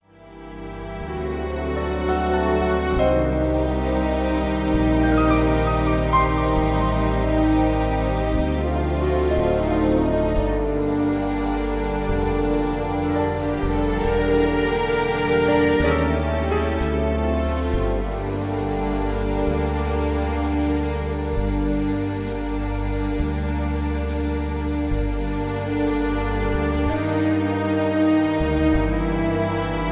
(Key-Gm) Karaoke MP3 Backing Tracks